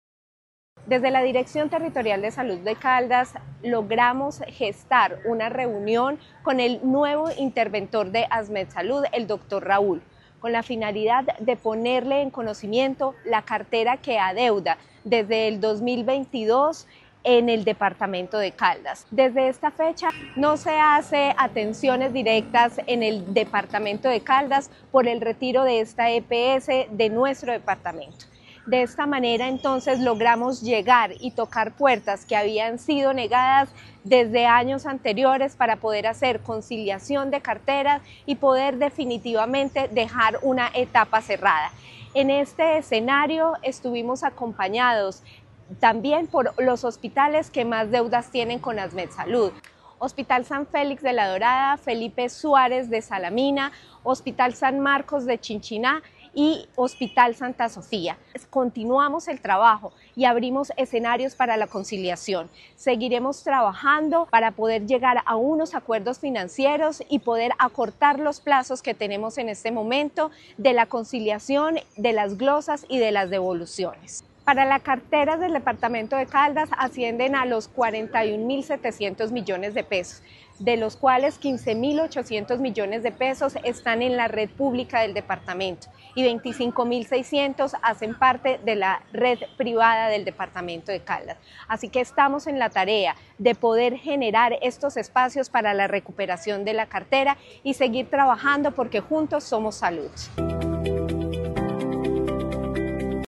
Natalia Castaño Díaz, directora de la DTSC
AUDIO-NATALIA-CASTANO-DIAZ-DIRECTORA-DTSC-TEMA-VISITA-A-ASMET-SALUD.mp3